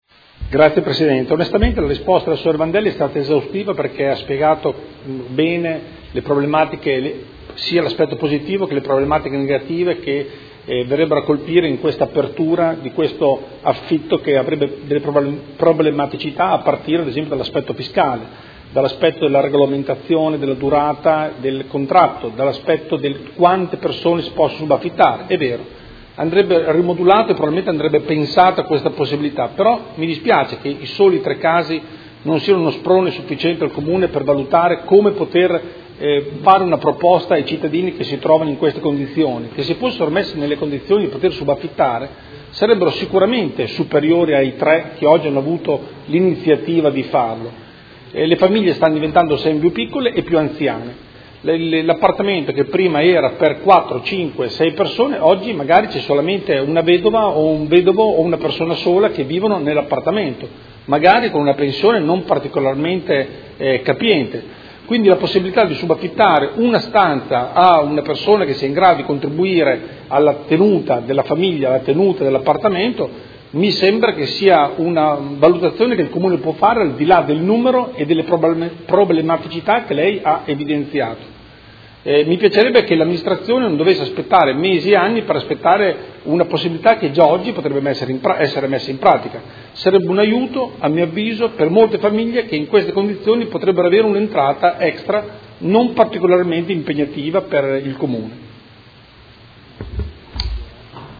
Seduta del 27/10/2016 Replica a risposta Assessora. Interrogazione del Consigliere Galli (F.I.) avente per oggetto: Edilizia convenzionata in area PEEP circa la volontà dell’Amministrazione di consentire ai proprietari di abitazioni in area PEEP di poter affittare gli immobili in modo frazionato o con presenza di usufruttuario